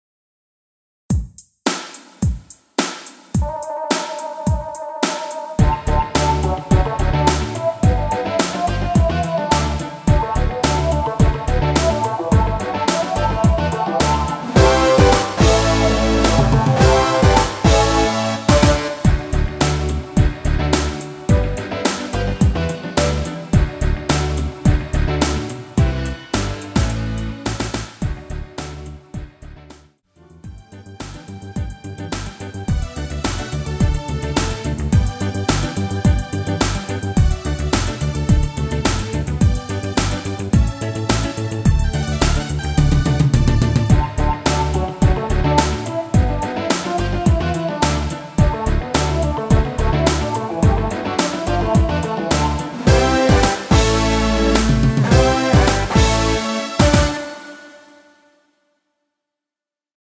원키에서(-2)내린 마지막 반복 되는 오오오~ 없이 엔딩을 만들었습니다.(미리듣기및 가사 참조)
◈ 곡명 옆 (-1)은 반음 내림, (+1)은 반음 올림 입니다.
앞부분30초, 뒷부분30초씩 편집해서 올려 드리고 있습니다.